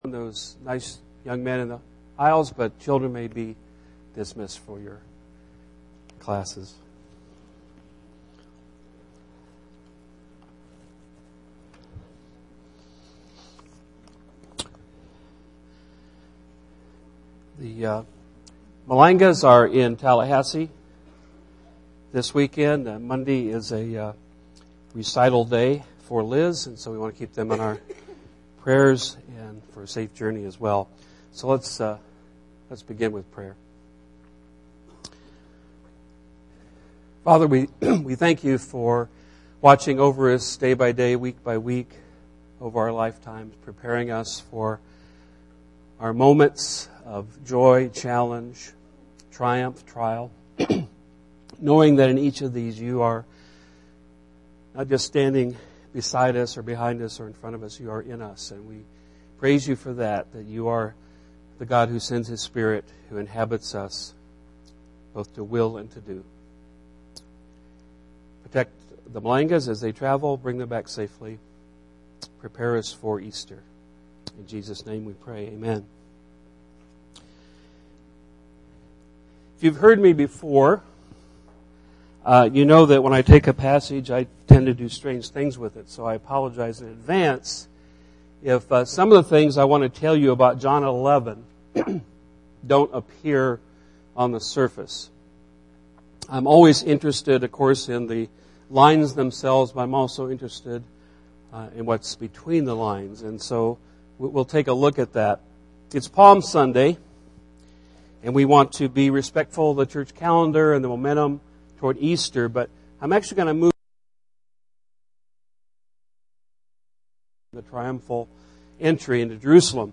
Listen to the CS Lewis Review podcast!